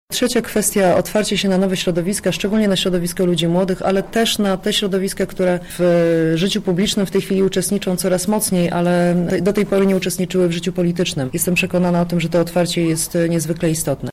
Podczas konferencji były również przedstawione wyzwania które stoją przed Platformą Obywatelską.
A o trzecim mówi posłanka Joanna Mucha.